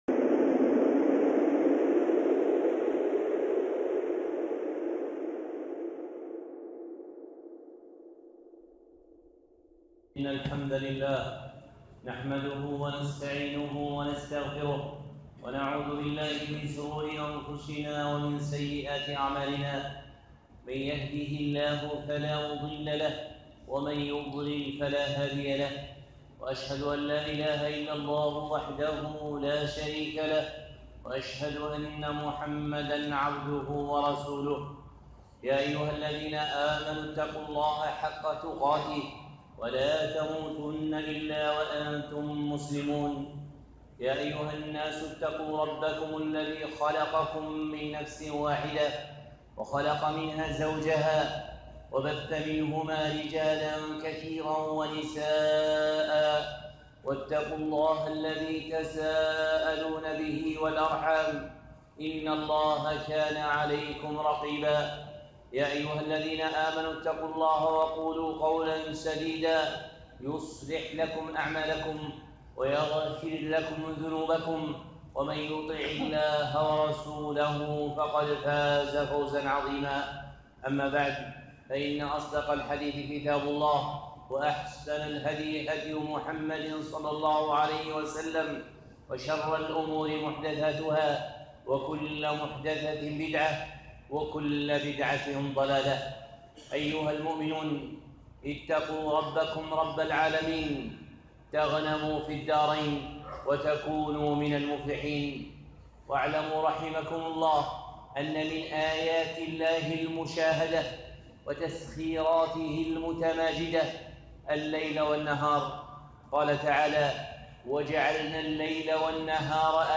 خطبة (الاعتبار بالليل والنهار